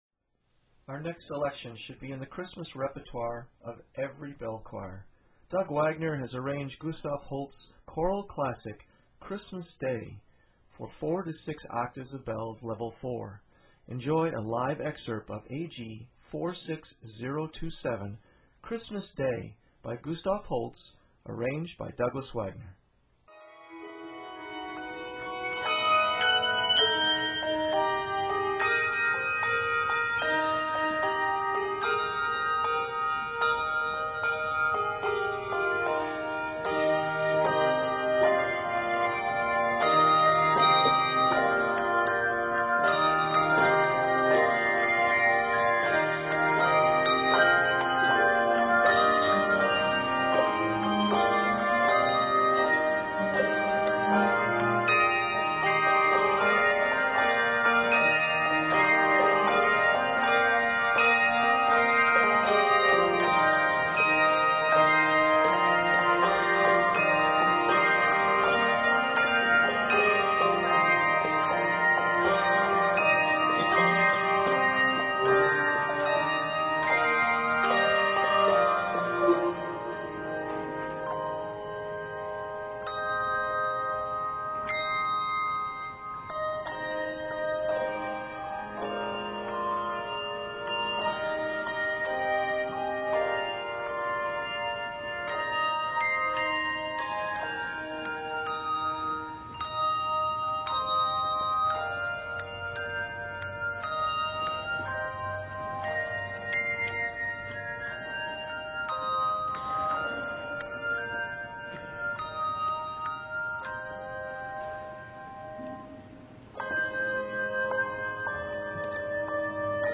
for bells